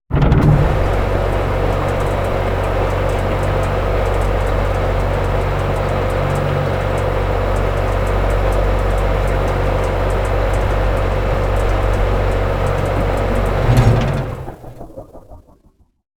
Landing4.wav